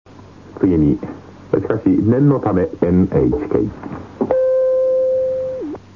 つぎはぎニュース
大昔のラヂヲ番組、タモリのオールナイトニッポンでオンエアされたモノです。
ソースは２０年程前のエアチェックテープです(^^;